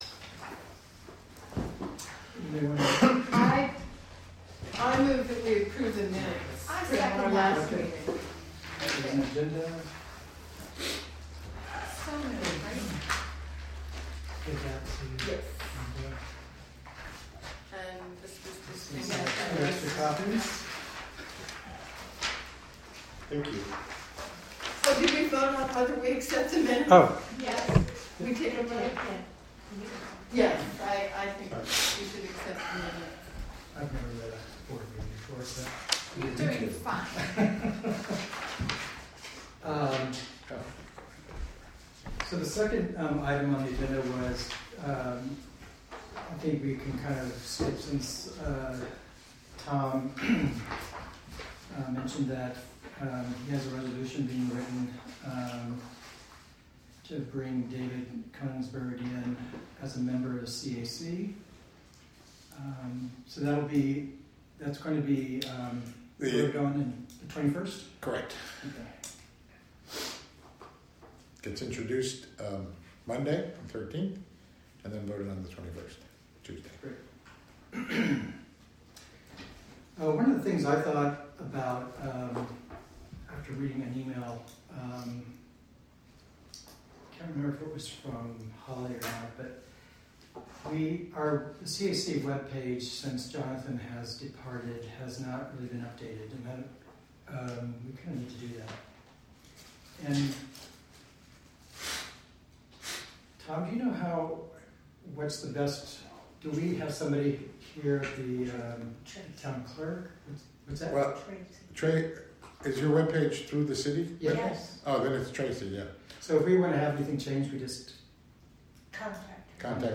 Recorded from a live webstream created by the Town of Catskill through the Wave Farm Radio app and WGXC.